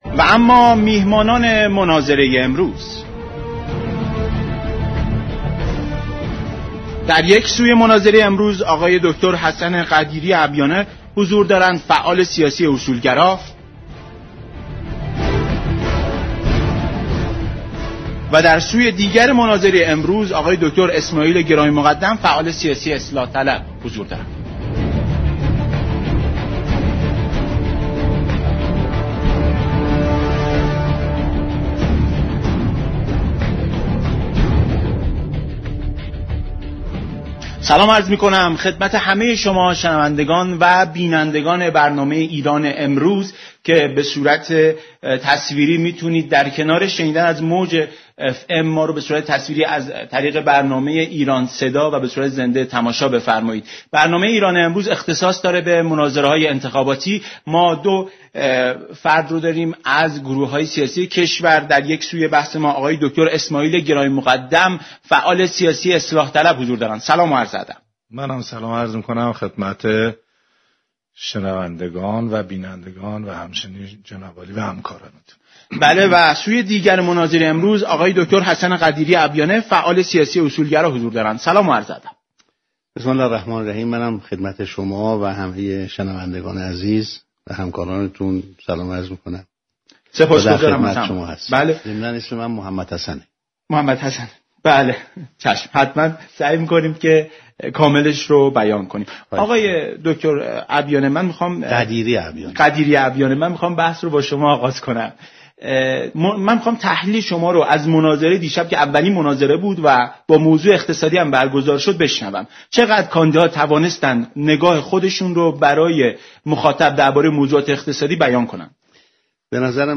امروز سه‌شنبه 29 خرداد، در چهارمین میز مناظره برنامه ایران‌امروز، رادیو ایران میزبان، حسن قدیری ابیانه فعال اصولگرا و اسماعیل گرامی‌مقدم فعال اصلاح‌طلب بود.